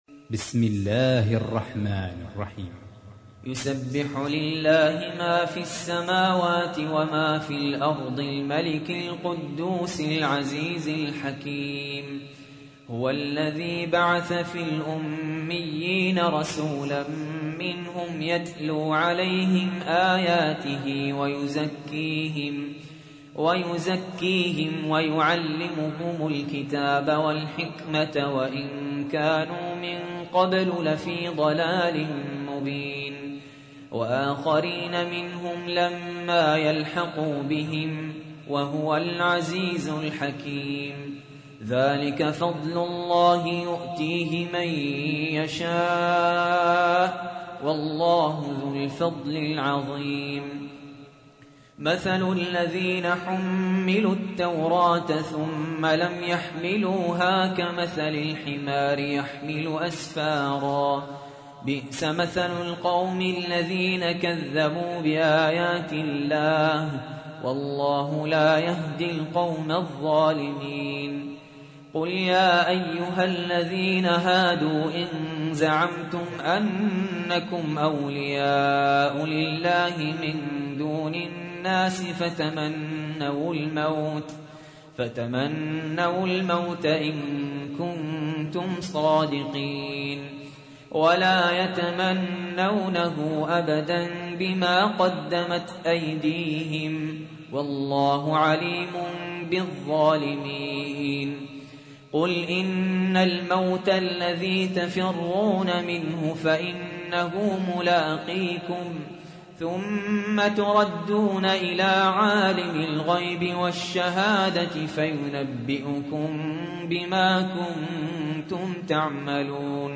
تحميل : 62. سورة الجمعة / القارئ سهل ياسين / القرآن الكريم / موقع يا حسين